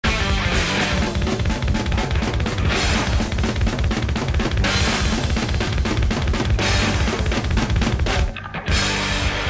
エンディングのダカドコが気持ちいい！）。